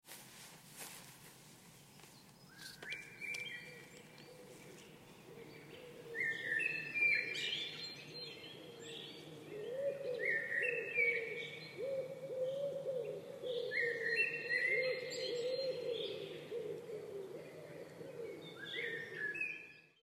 In this nature short video, I recorded the peaceful forest ambience of a German woodland at dawn. The forest soundscape is alive with bird song, from the gentle blackbird chirping in the trees to the distant, calming call of the eagle owl. Together these woods sounds create an ambient forest atmosphere that feels both tranquil and grounding. This clip captures the essence of forest nature in its purest form, with no loops or artificial effects, just real field recording straight from the heart of the forest in Germany. You’ll hear bird sounds blending into a rich birds ambience, a natural forest music shaped by the rhythms of wildlife and the woods ambience around them.